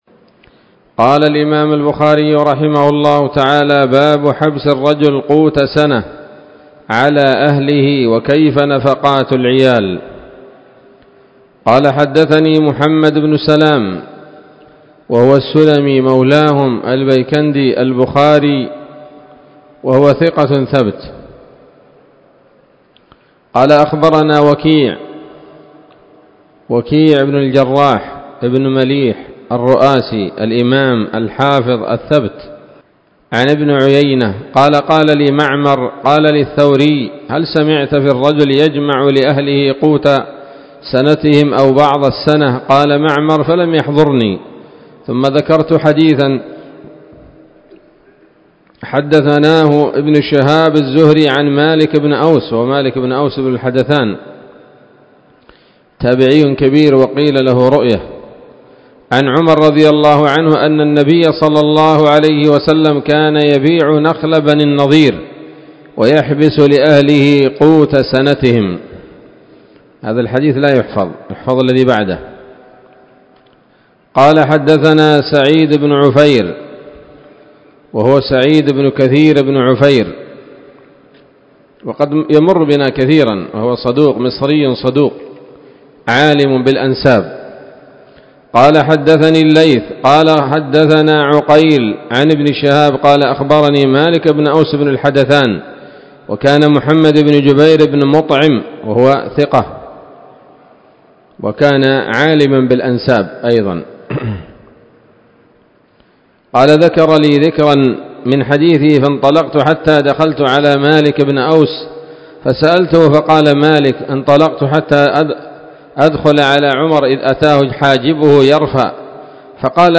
الدرس الثالث من كتاب النفقات من صحيح الإمام البخاري